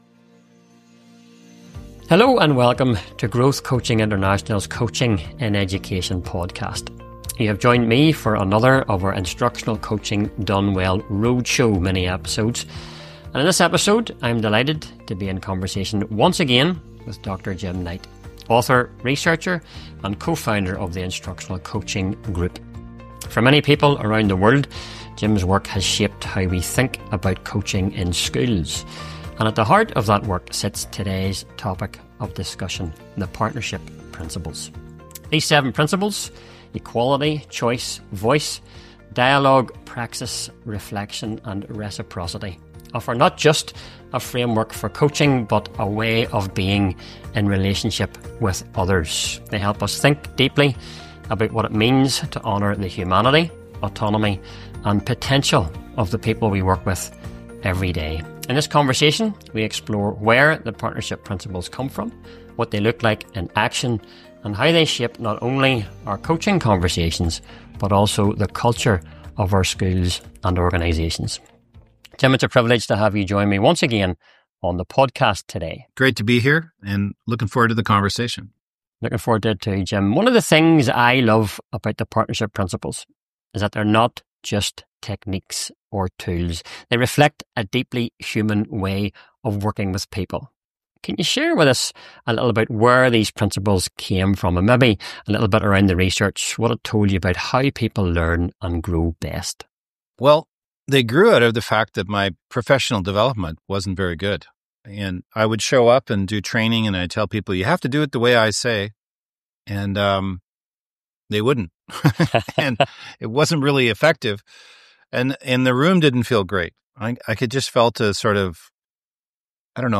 In this conversation, we explore where the Partnership Principles come from, what they look like in action, and how they can shape not only our coaching conversations — but also the culture of our schools and organisations.